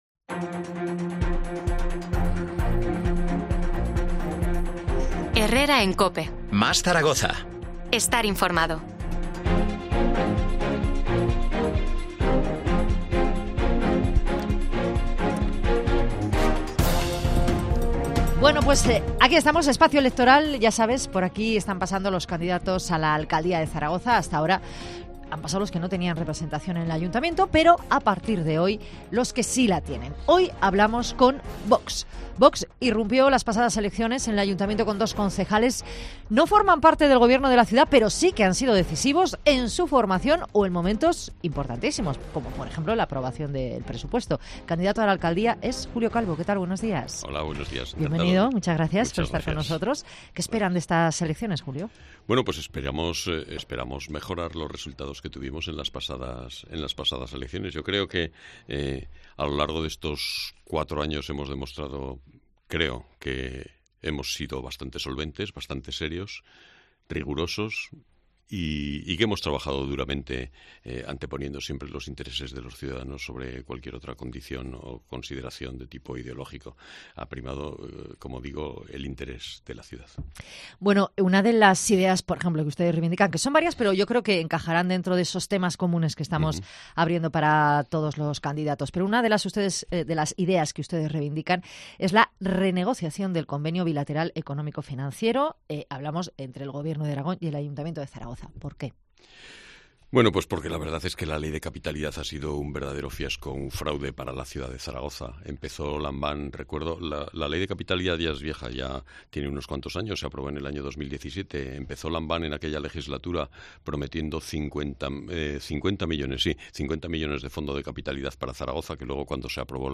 El candidato a la alcaldía de Zaragoza por VOX ha pasado por los micrófonos de COPE Zaragoza para exponer sus propuestas de cara a la próxima cita del 28n de mayo
Entrevista a Julio Calvo, candidato de VOX a la alcaldía de Zaragoza